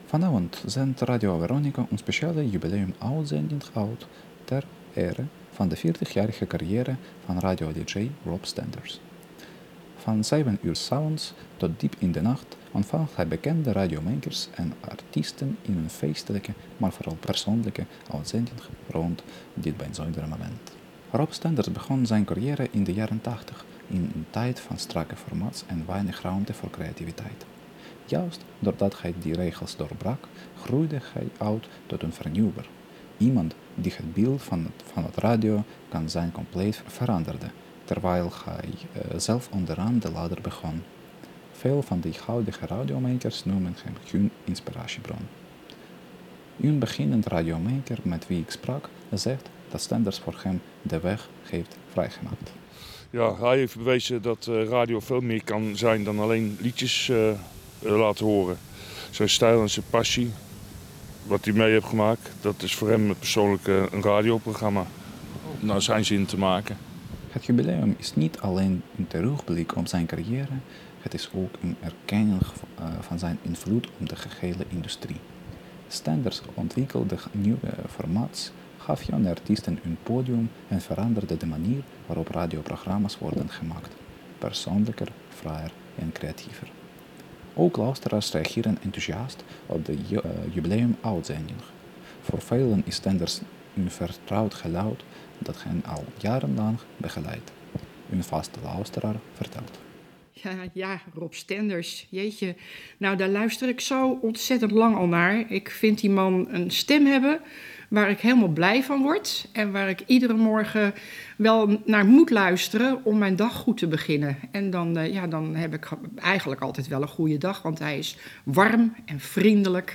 Rob-Stenders-audiobericht.mp3